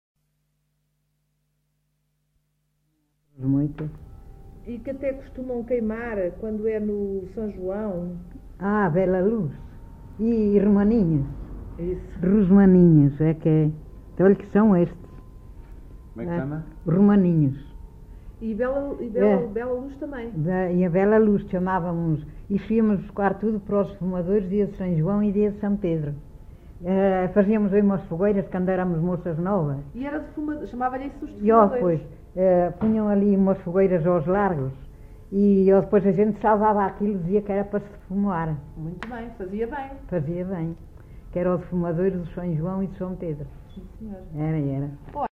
LocalidadeAssanhas (Celorico da Beira, Guarda)